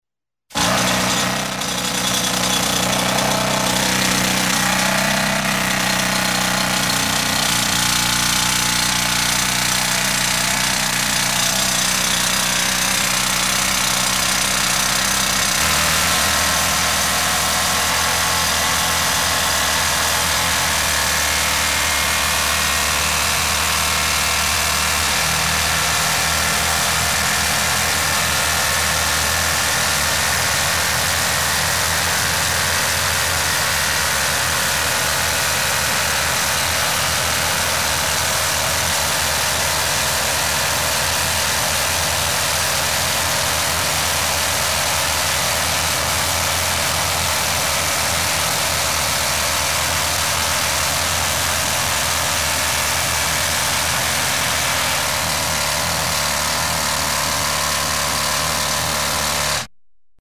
Шахта: работа проходчиков буром
Тут вы можете прослушать онлайн и скачать бесплатно аудио запись из категории «Производство, заводы».